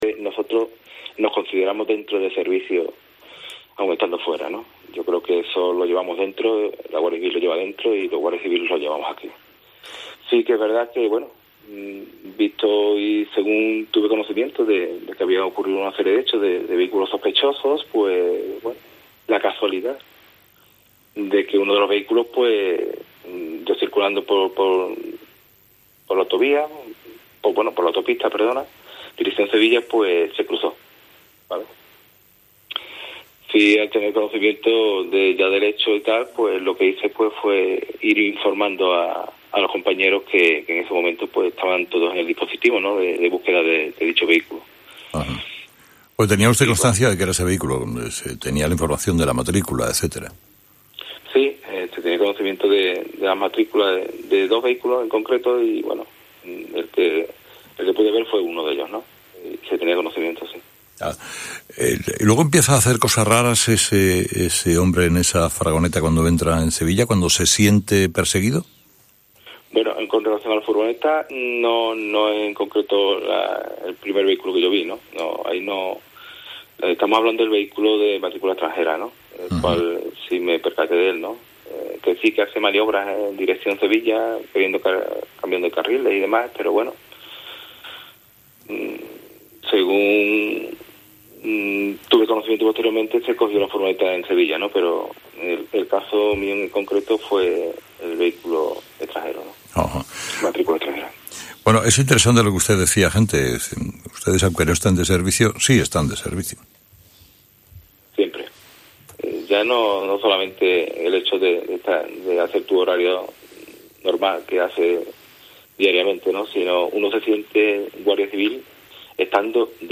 Agente de la Guardia Civil sobre la persecución entre Chipiona y Sevilla